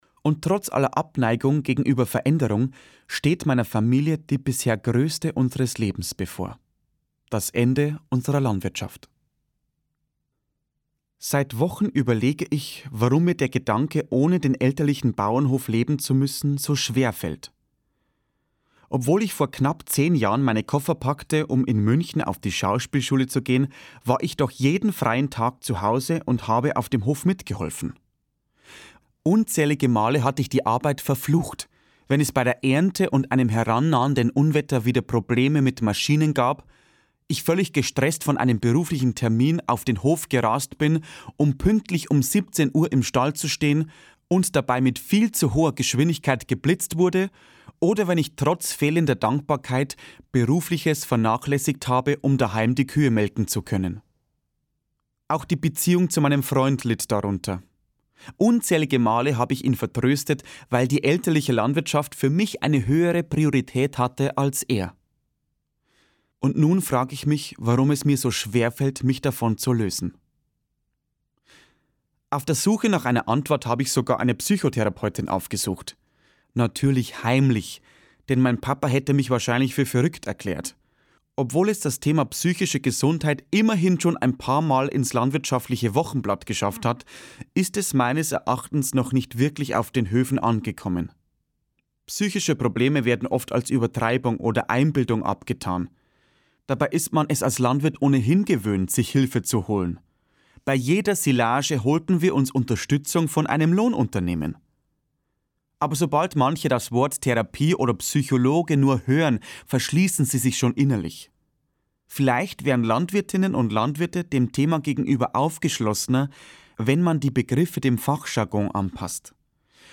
Ein Hörbuch, das mit Herzenswärme und Humor Stadt und Land ein bisschen näher zueinanderbringt.
Martin Frank ist der beste Erzähler seiner eigenen Geschichten und liest das Hörbuch wie schon sein erstes Buch Oma, ich fahr schon mal den Rollstuhl vor selbst.
Die Letzte macht das Licht aus Gelesen von: Martin Frank